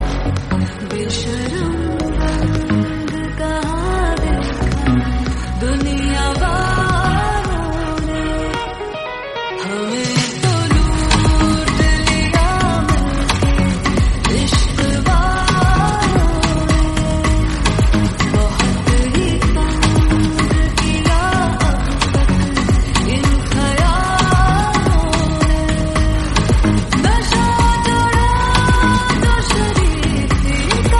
hot & trending Bollywood
Stylish ringtone with romantic vibes